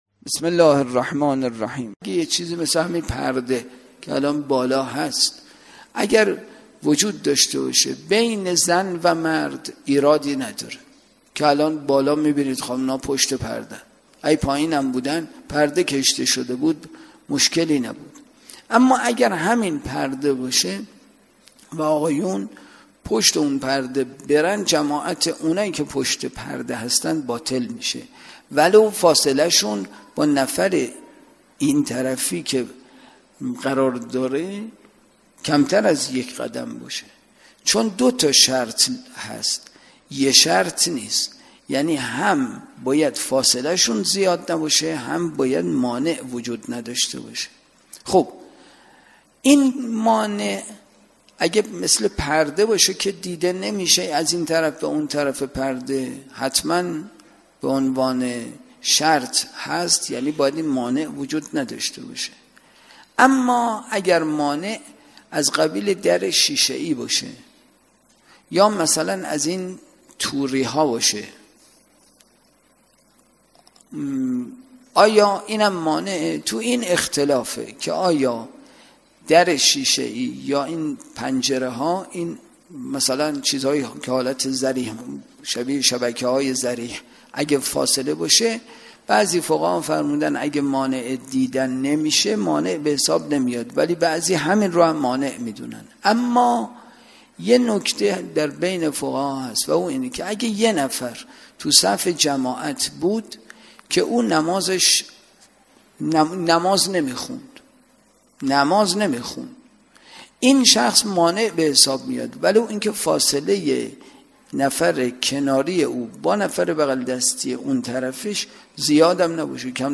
اطلاعات آلبوم سخنرانی
برگزارکننده: مسجد اعظم قلهک